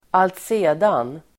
Ladda ner uttalet
alltsedan.mp3